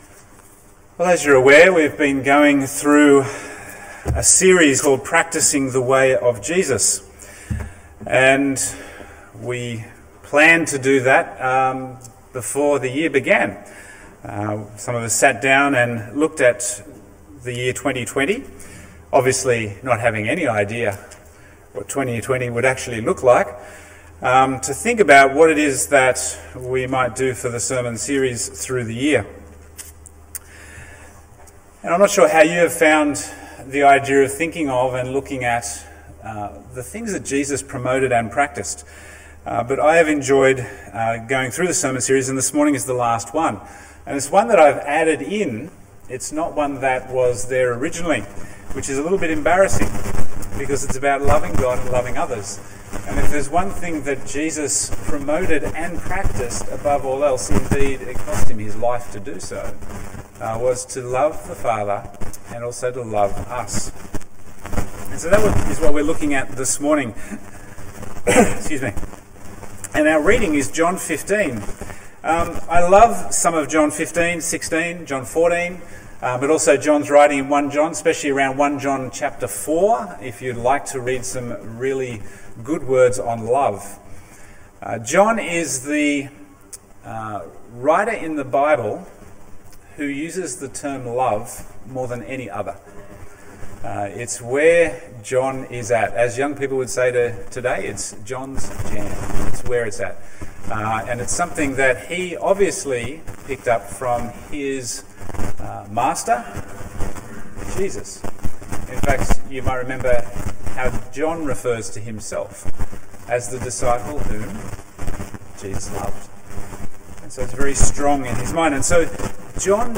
Bible Text: John 15:9-17 | Preacher